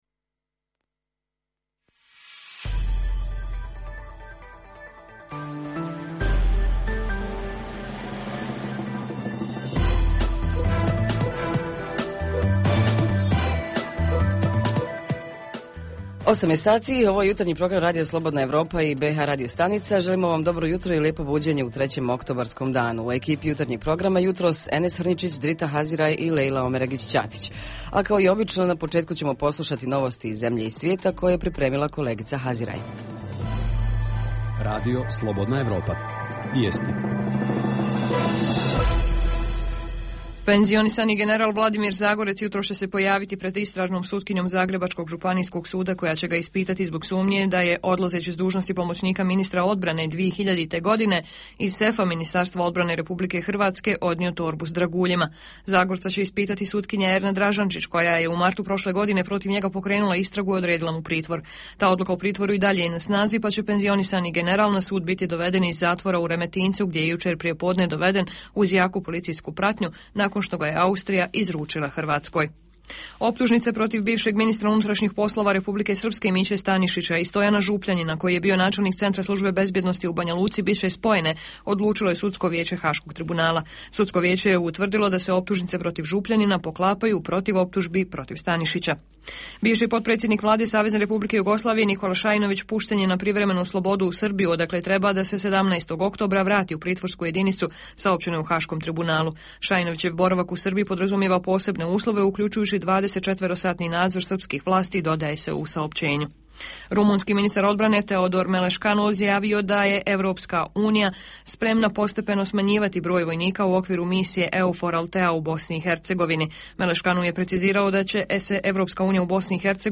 Jutarnji program za BiH koji se emituje uživo, a ovog jutra pitamo: kako protiv nepismenosti? Redovna rubrika Radija 27 petkom je “Za zdrav život". Redovni sadržaji jutarnjeg programa za BiH su i vijesti i muzika.